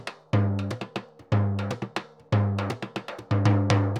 Surdo 2_Candombe 120_2.wav